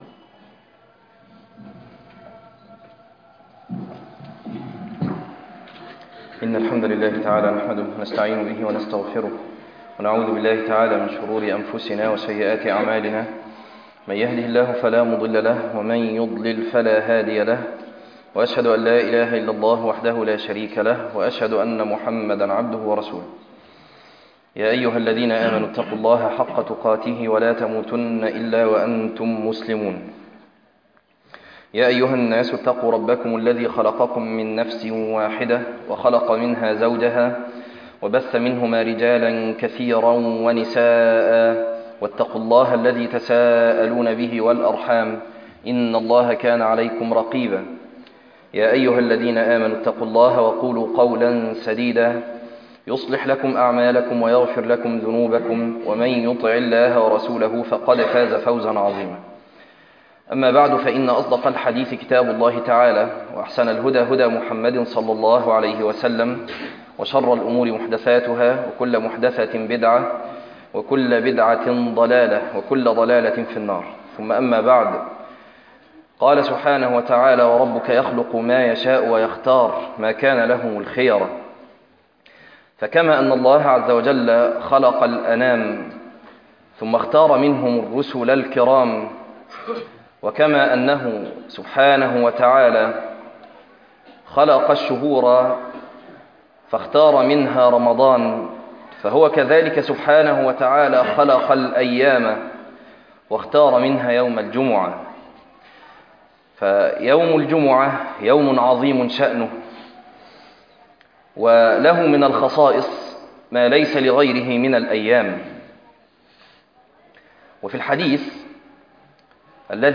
تفاصيل المادة عنوان المادة خصائص الجمعة - خطبة تاريخ التحميل الأثنين 13 ابريل 2026 مـ حجم المادة 10.96 ميجا بايت عدد الزيارات 20 زيارة عدد مرات الحفظ 9 مرة إستماع المادة حفظ المادة اضف تعليقك أرسل لصديق